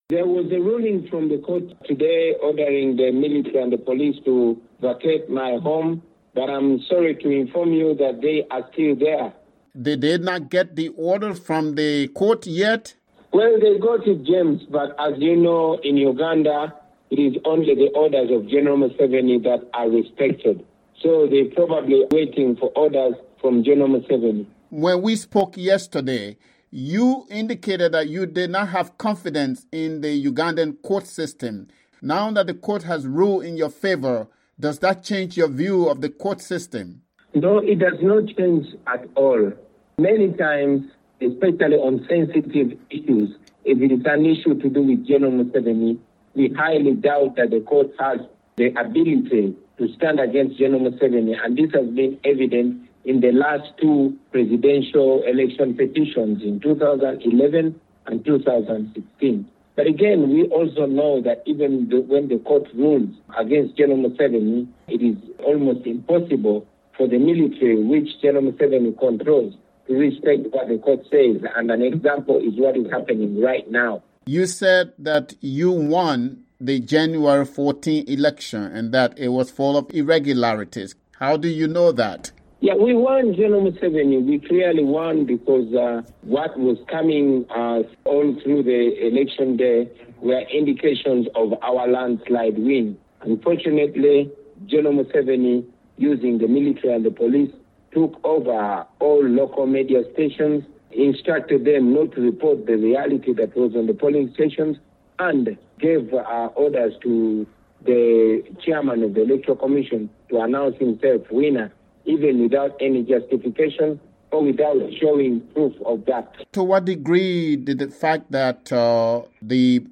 VOA Interview: Uganda's Bobi Wine Reacts to Court Ruling